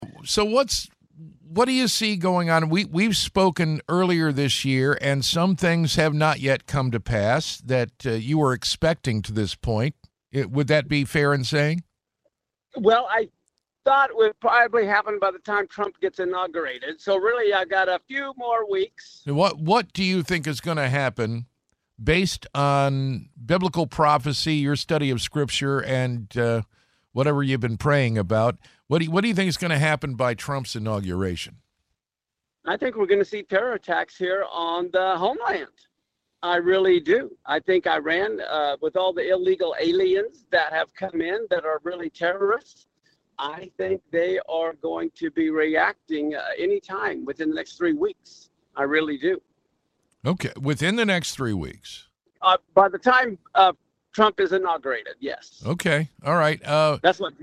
Service Type: Interviews